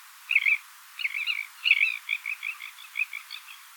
La presencia de estos sonidos puede indicar en algunas especies animales dominancia, apareamiento o cortejo; llamados de alerta al peligro; reconocimiento entre madres y crías etc. Este repositorio digital contiene grabaciones de fauna silvestre residente en la península de Baja California, resultado del proyecto de investigación en el área natural protegida Sierra de la Laguna.
Thalasseus_maximus.mp3